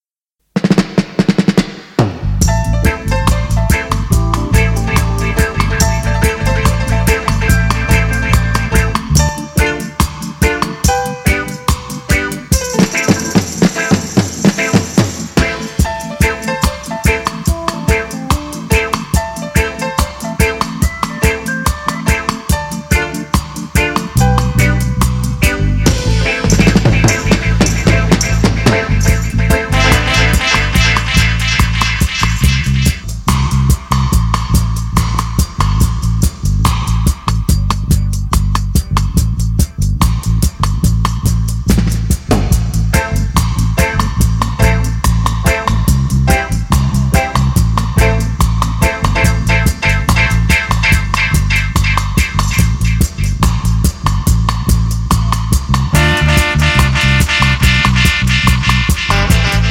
11 song dub LP.
Killer and rare late '70s dub LP
direct from master tape